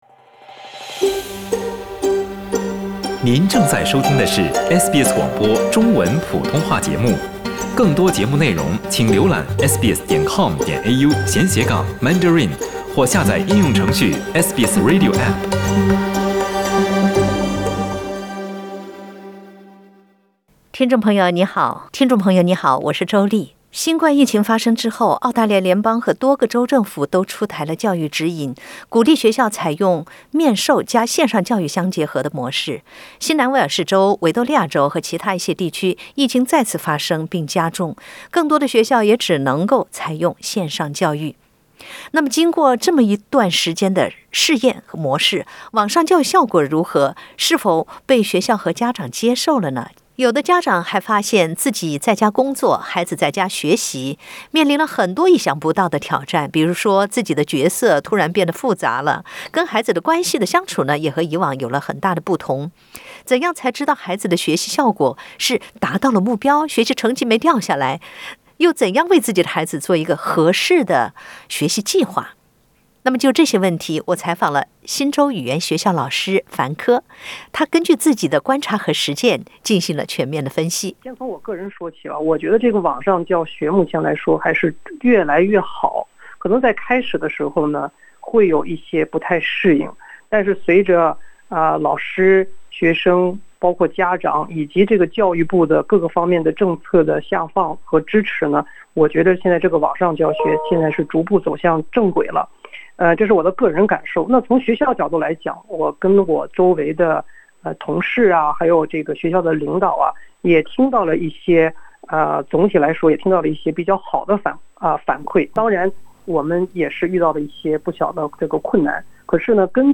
孩子在家学习，家长如何了解孩子是否达到了学习目标？（点击上图收听采访）